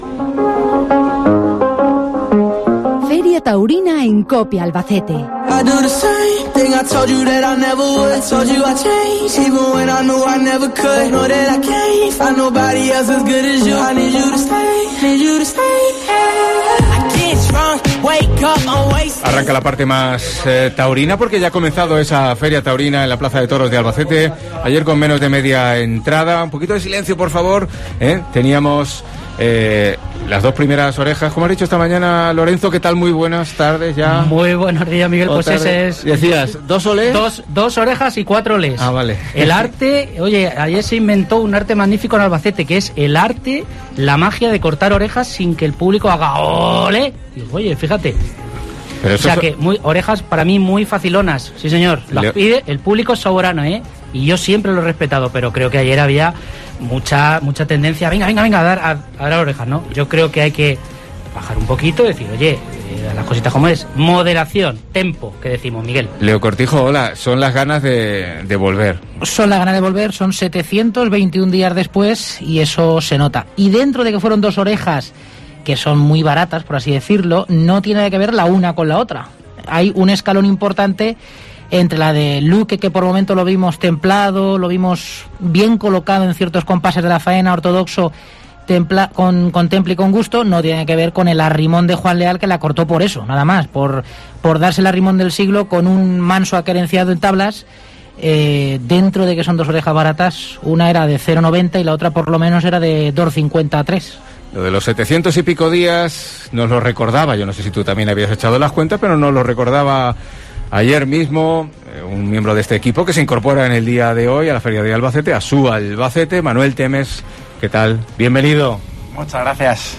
La tertulia taurina de COPE